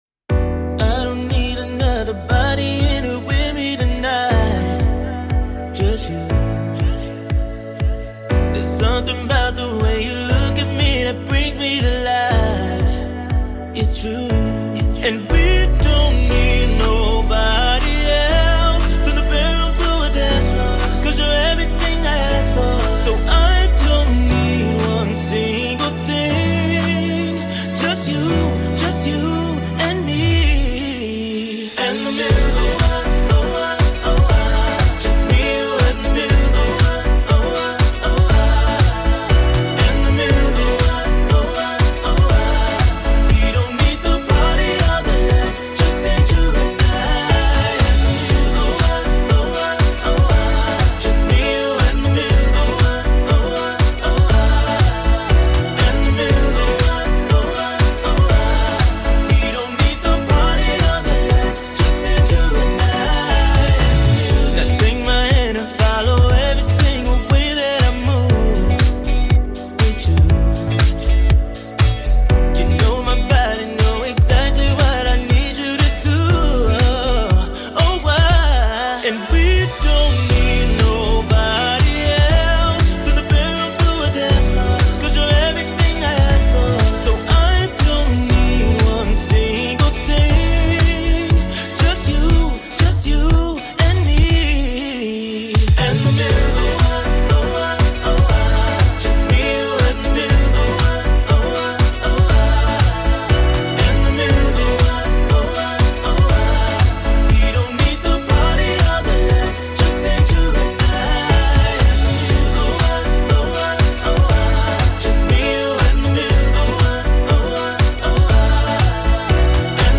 Full Mix: The complete track as you originally produced it.